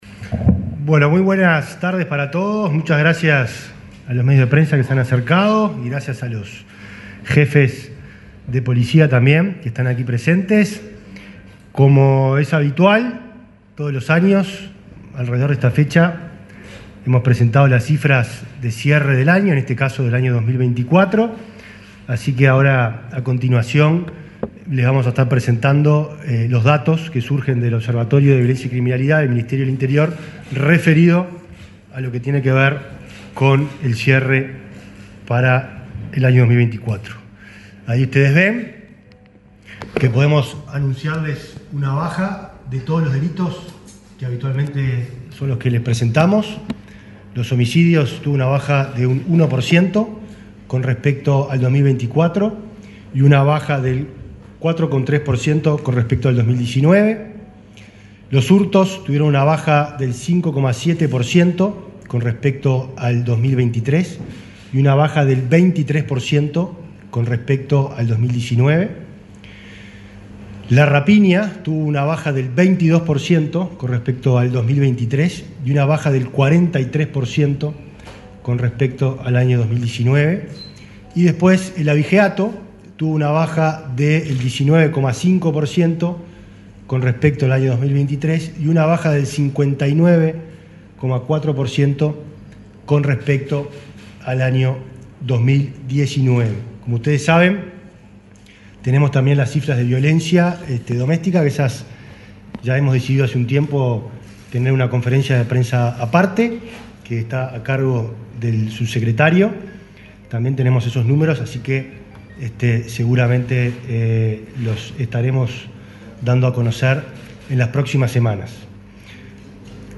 Palabras del ministro del Interior, Nicolás Martinelli